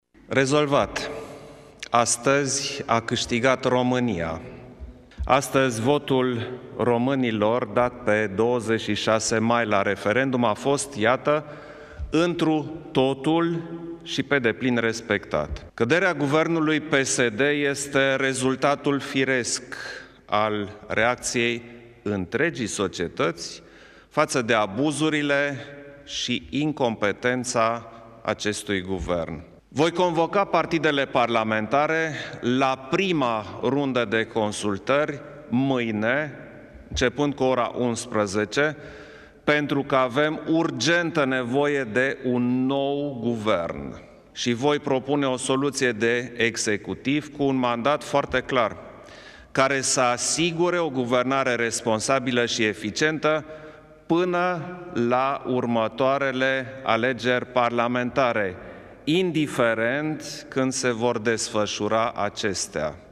Președintele Klaus Iohannis a declarat, în urmă cu puțin timp, că este nevoie urgent de un Guvern, după ce moţiunea de cenzură a fost adoptată în Parlament.
10-oct-decl-iohannis.mp3